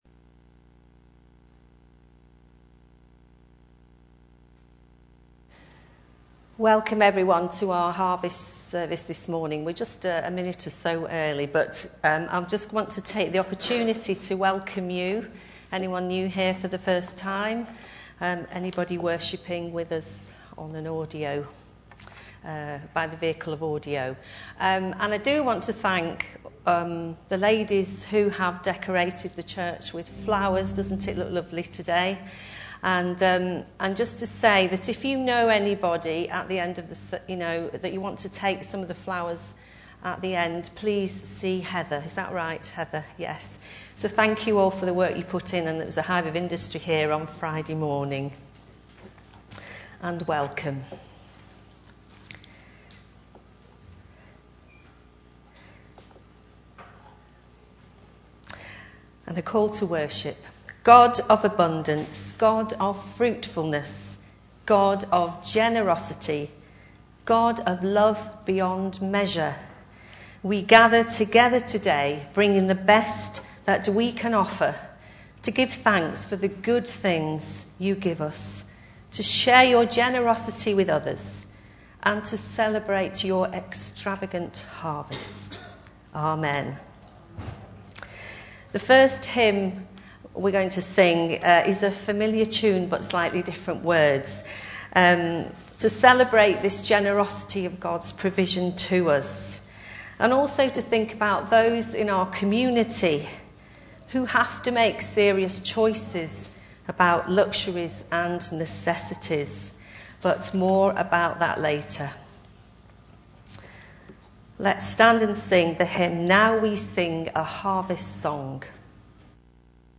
21 09 26 Harvest Festival
Genre: Speech.